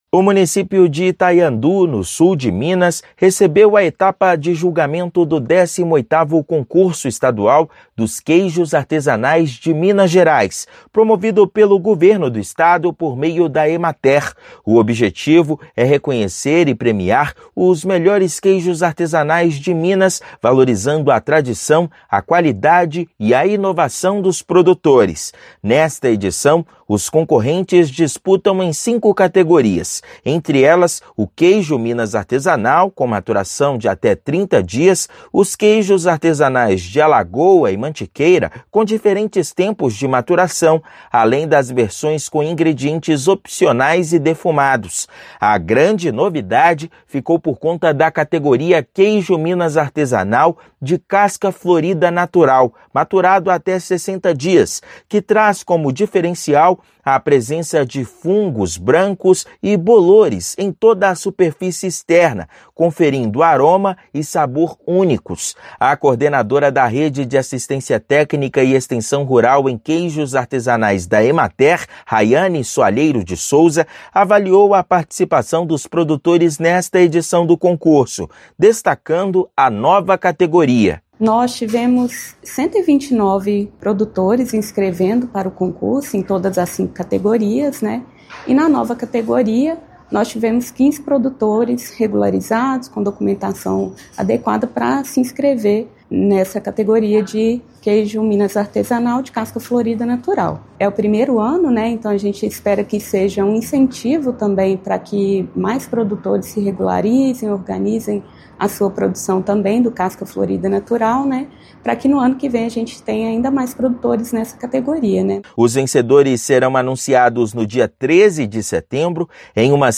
Estão na disputa 129 produtores e a novidade é a categoria do queijo artesanal de casca florida natural. Ouça matéria de rádio.